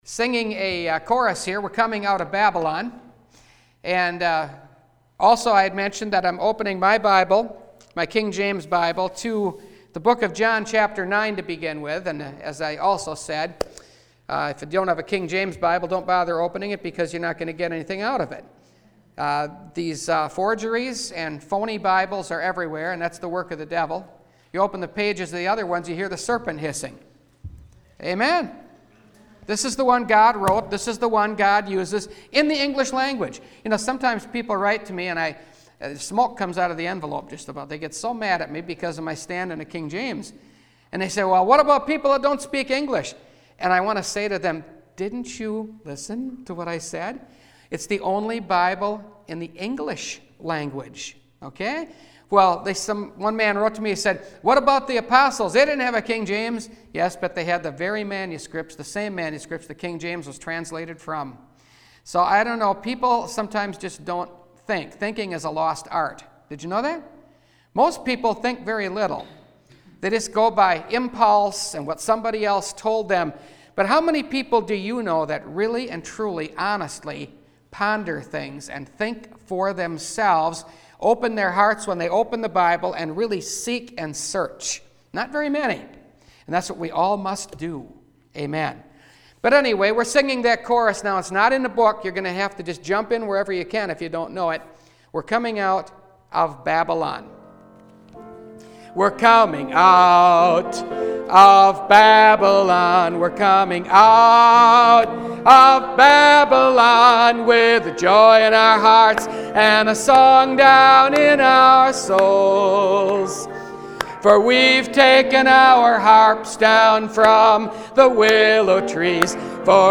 Fighting For Faith – Last Trumpet Ministries – Truth Tabernacle – Sermon Library
Newly Added Service Type: Sunday Morning « The Trial Of Your Faith